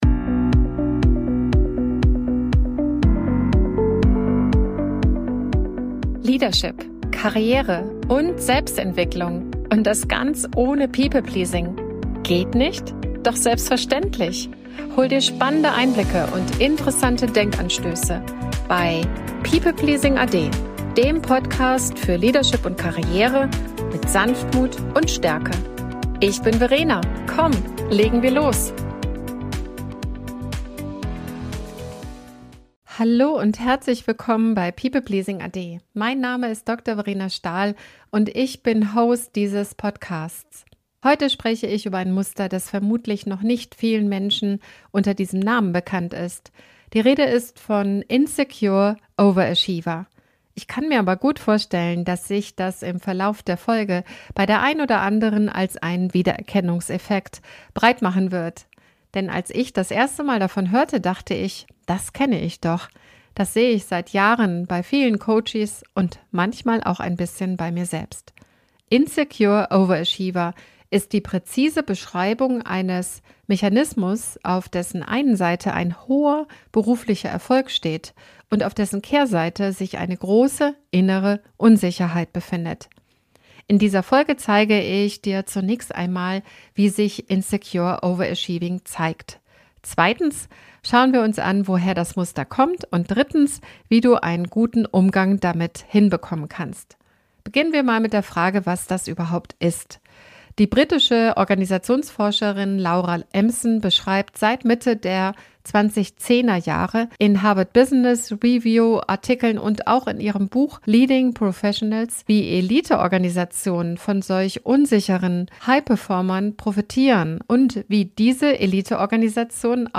In dieser Solo-Folge nehme ich dich mit in die innere Dynamik dieses Musters: - Insecure Overachieving – warum hohe Leistungsbereitschaft und zerbrechlicher Selbstwert so häufig zusammen auftreten.